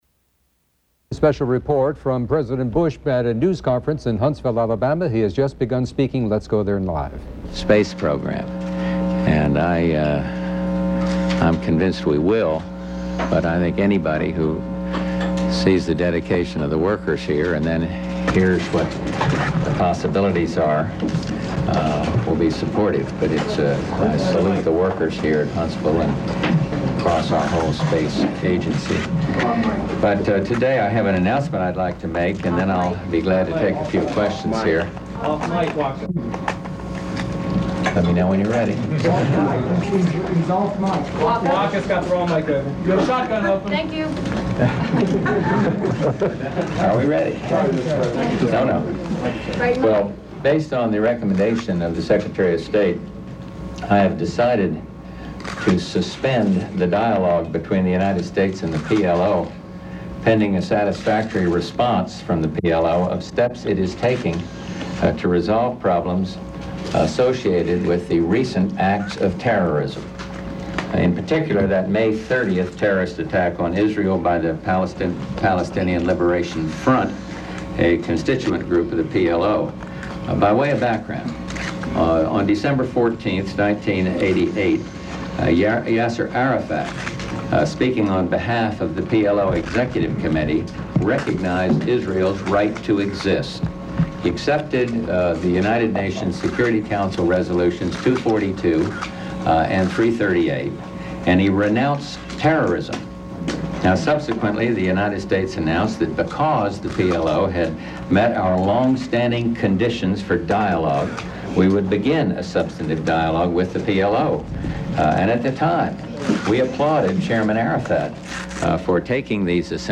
President Bush calls a press conference in Huntsville, Alabama